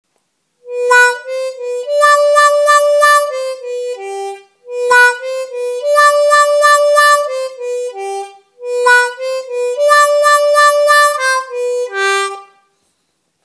Сыграть музыкальную фразу -3_+4-3-4_ _ _ _+4-3-2, делая эффект вау-вау в указанных местах.